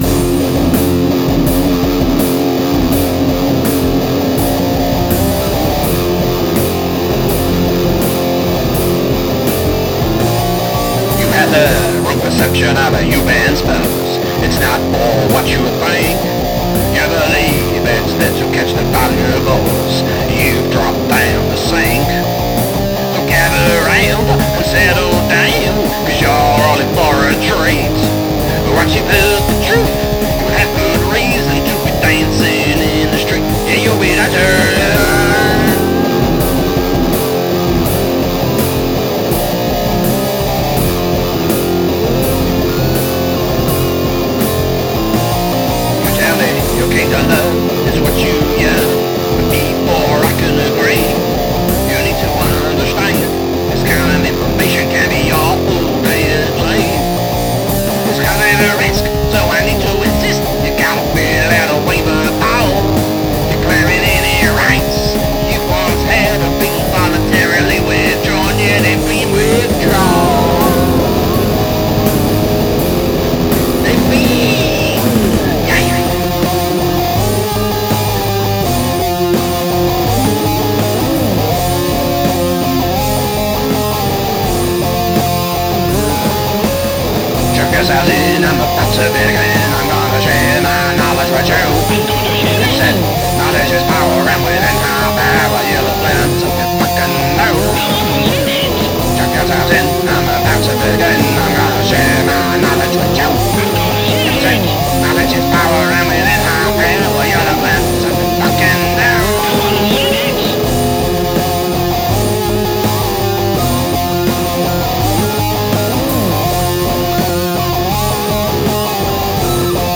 Except explosion by a rather unfortunate incident